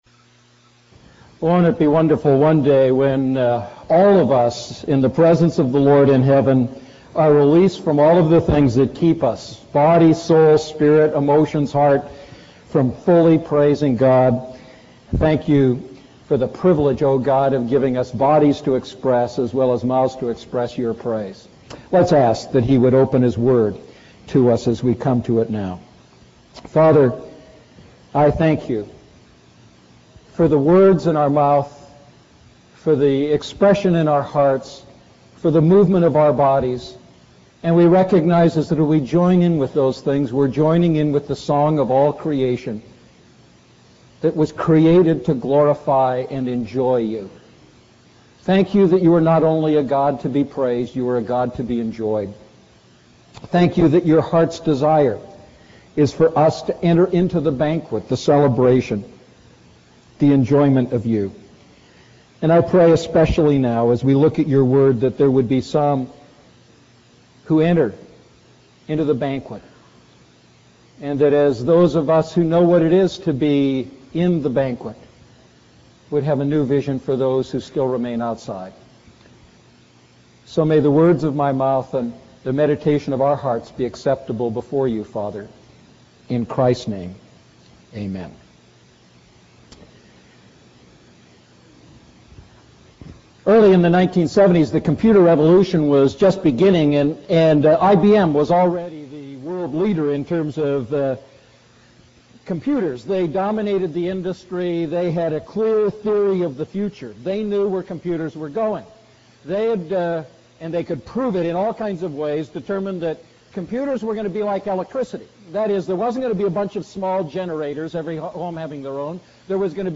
A message from the series "Luke Series II."